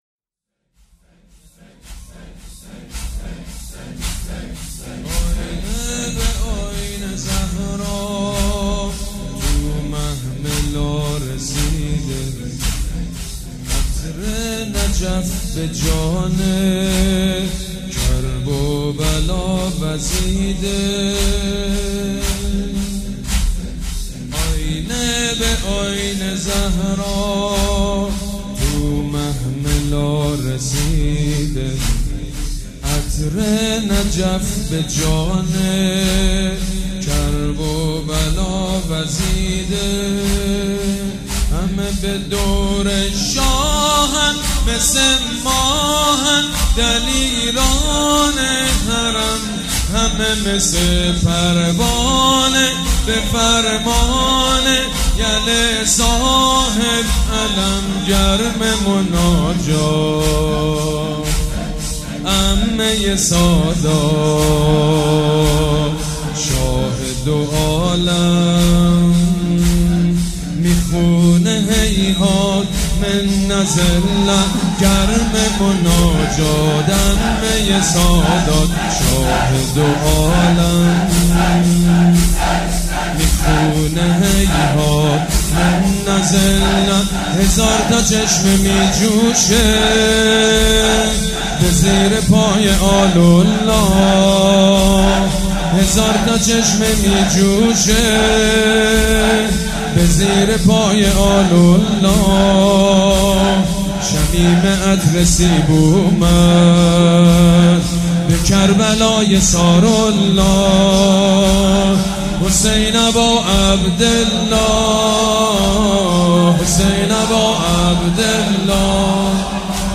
مداحی شب دوم محرم 99 سید مجید بنی فاطمه
زمینه آیینه به آیینه زهرا تو محفل ها رسیده